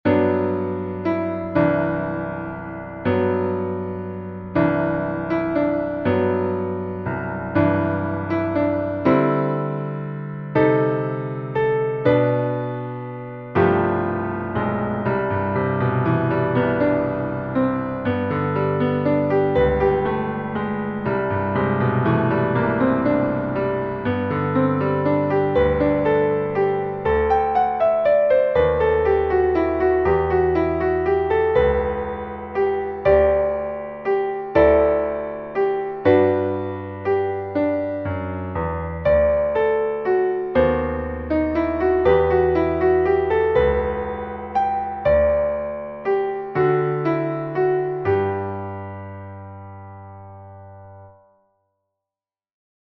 Tonalità: re maggiore
Metro: 6/8
spartito e base musicale per chitarra
arrangiato per chitarra